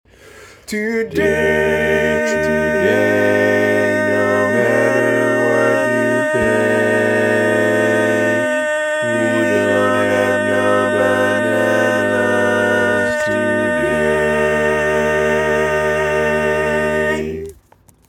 Key written in: E♭ Major
How many parts: 4
Type: Barbershop
All Parts mix: